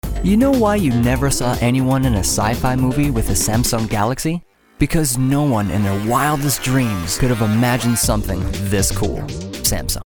Male
Yng Adult (18-29), Adult (30-50)
Radio Commercials